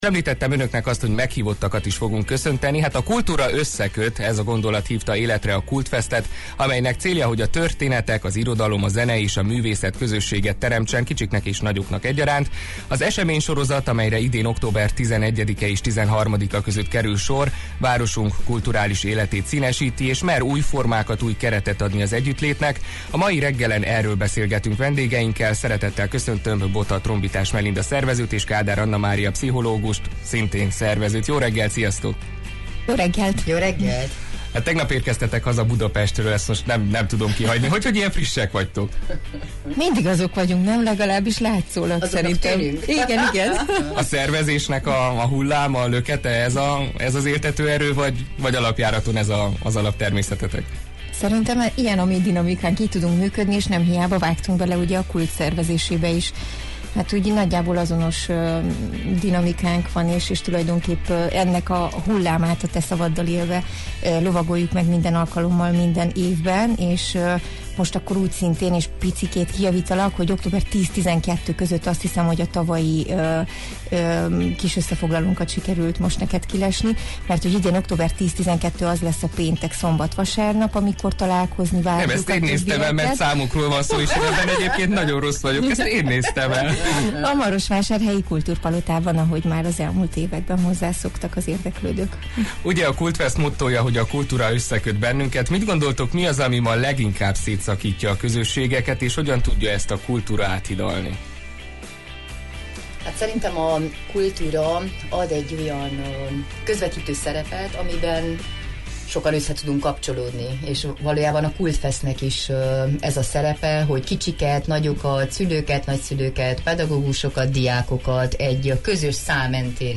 A mai reggelen erről beszélgettünk vendégeinkkel